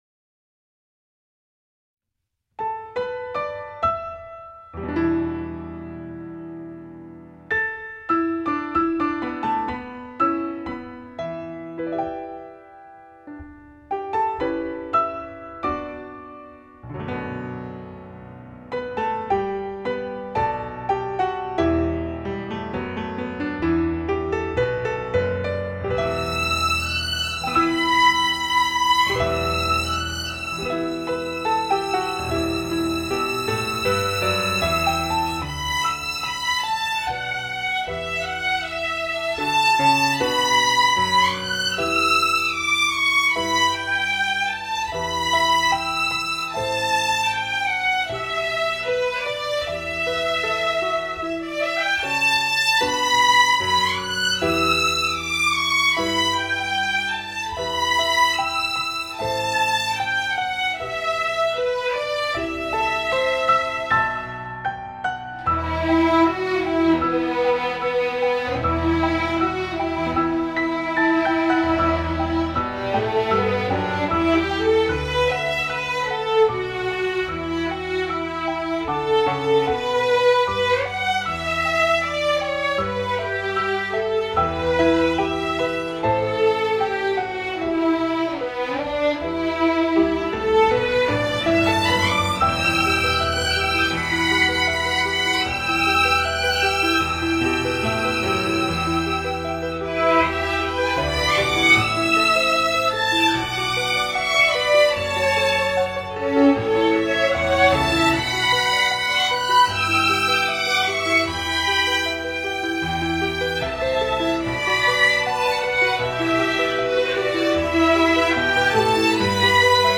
[9/1/2011]纯音乐【山丹丹花开红红艳艳】